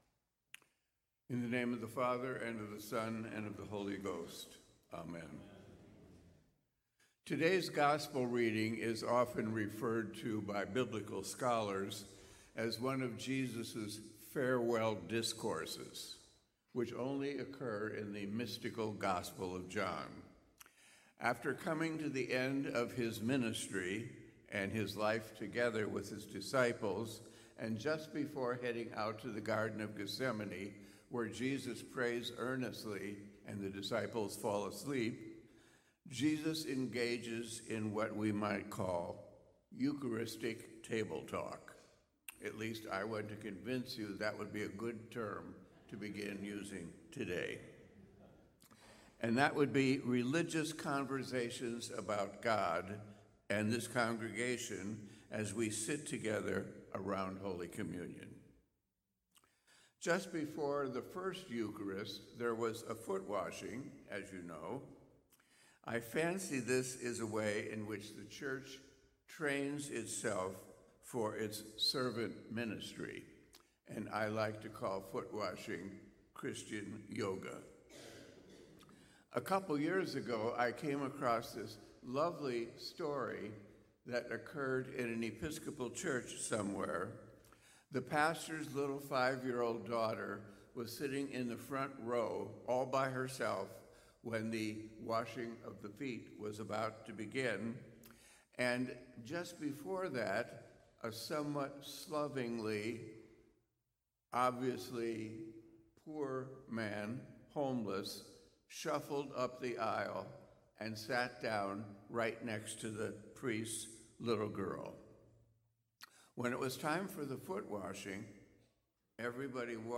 As you listen to the sermon, here are some questions you can reflect on to deepen your spiritual connection with God: